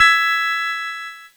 Cheese Chord 22-E4.wav